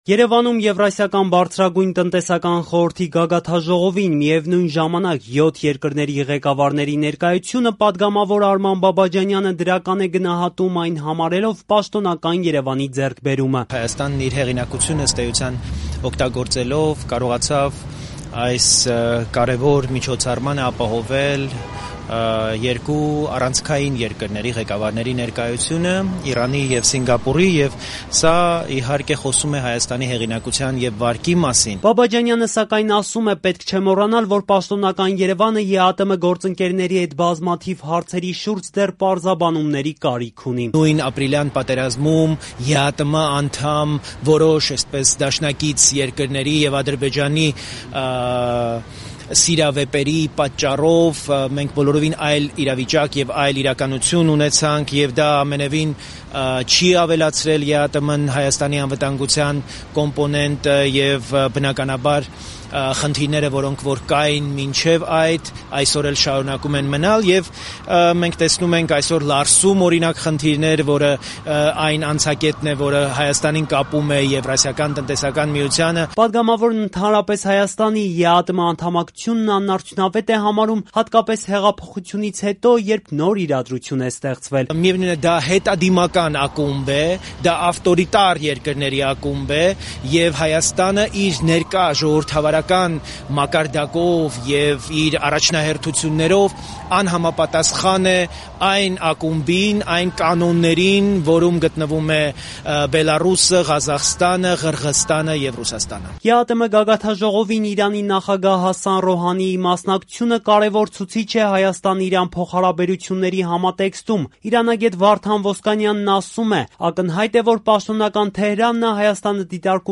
«Երեք աշխարհ հանդիպեցին Երևանում»․ պատգամավորները, իրանագետը մեկնաբանում են ԵԱՏՄ գագաթնաժողովը
Ռեպորտաժներ